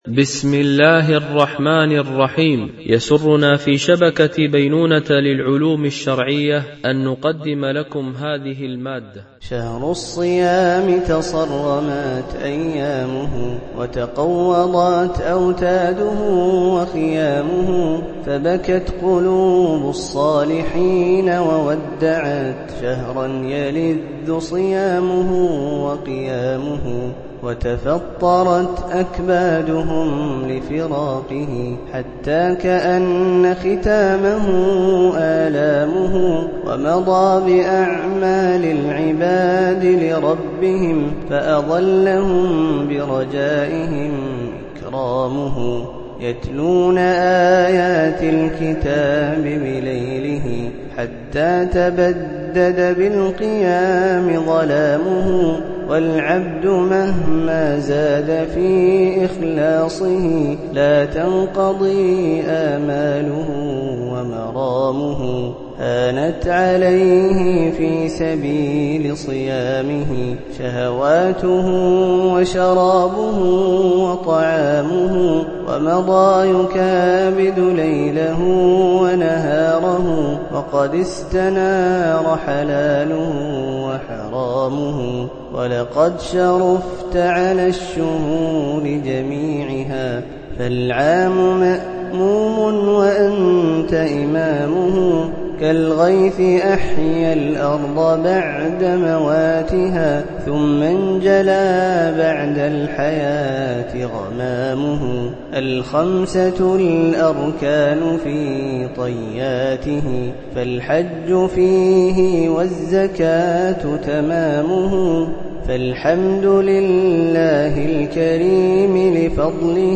القسم: شهر رمضان حمّل المنظومة